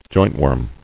[joint·worm]